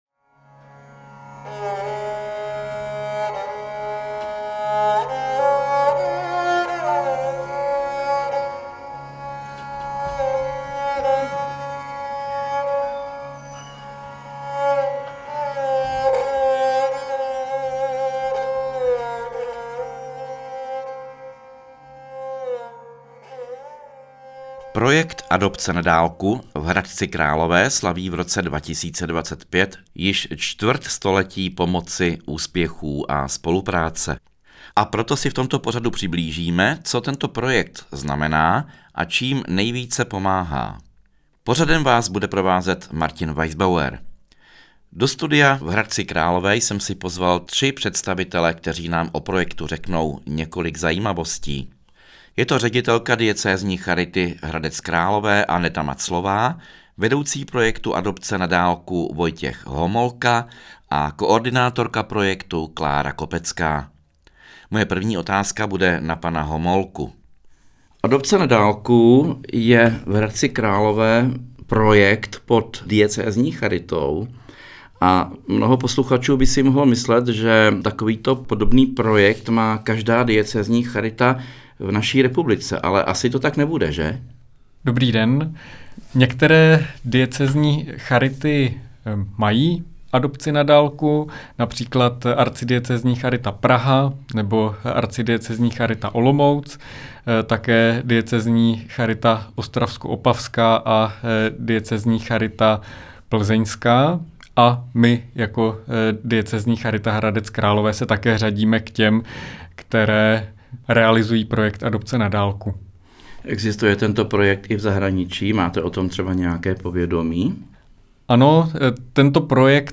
Rozhovor v Radio Proglasu